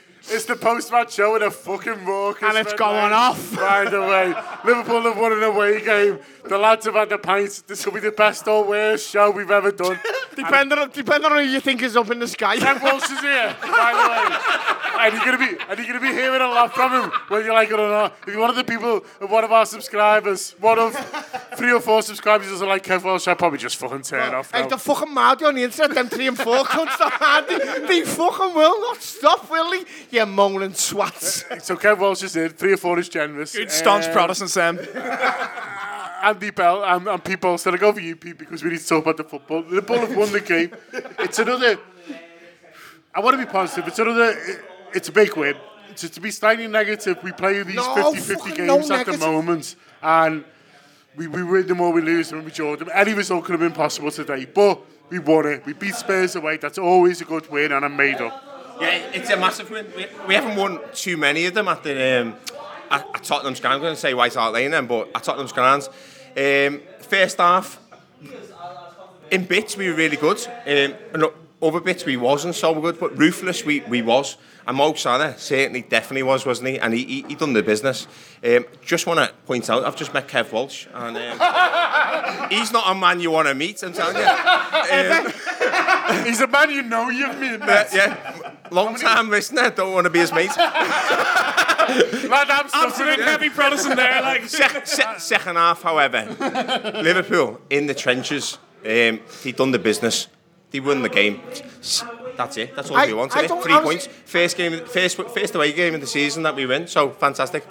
Below is a clip from the show – subscribe for more Tottenham v Liverpool reaction…